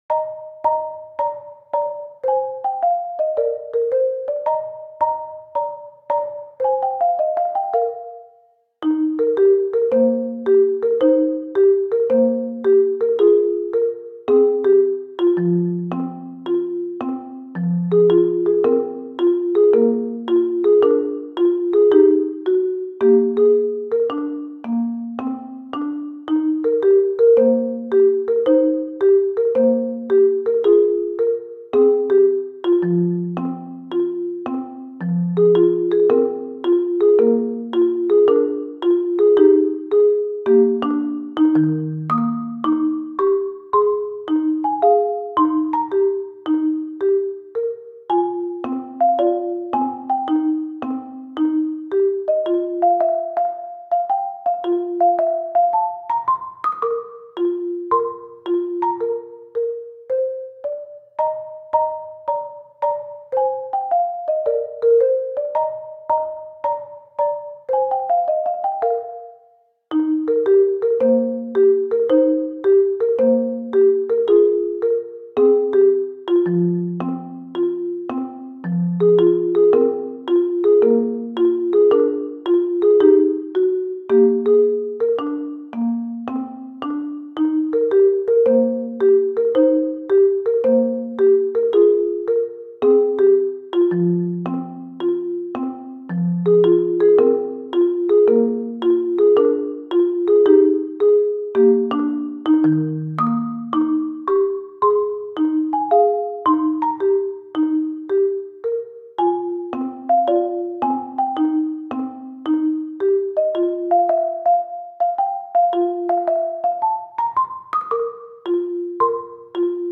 ogg(L) かわいい マリンバ アレンジ
よりゆったりと。